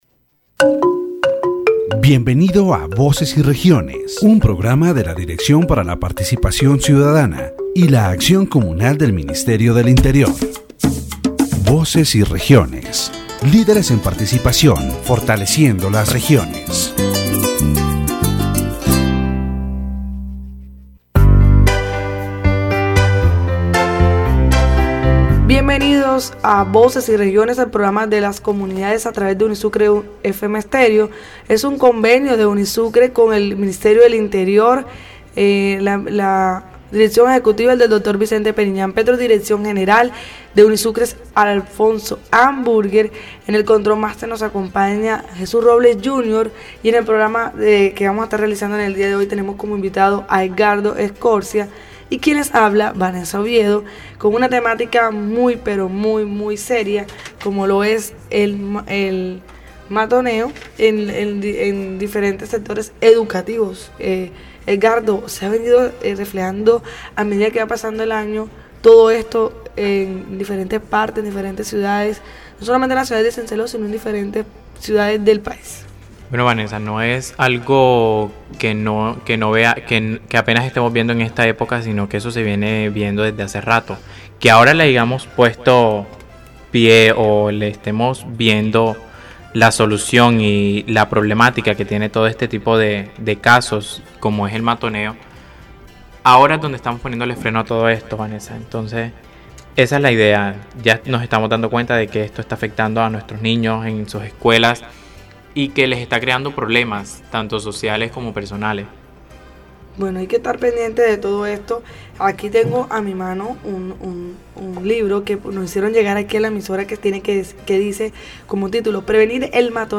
The radio interview focuses on the topic of bullying in Colombia. The causes, consequences, legislation on the subject, and preventive measures for victims are discussed.